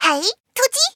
文件 文件历史 文件用途 全域文件用途 Chorong_skill_01.ogg （Ogg Vorbis声音文件，长度0.9秒，130 kbps，文件大小：15 KB） 源地址:地下城与勇士游戏语音 文件历史 点击某个日期/时间查看对应时刻的文件。